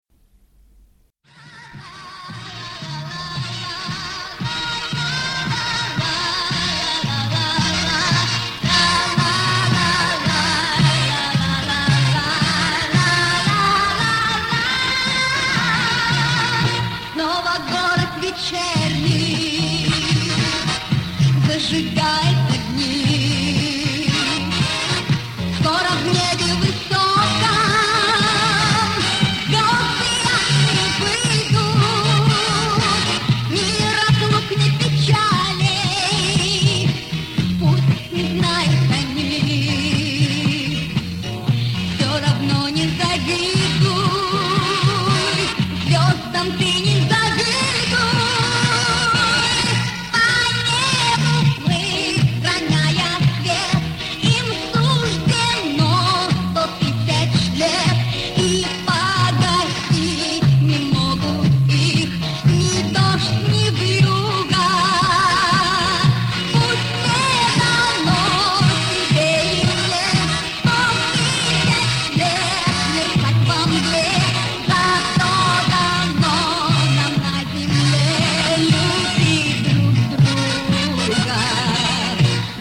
Да здесь трудно понять...качество не фонтан!
Это по-моему какой то ВИА поёт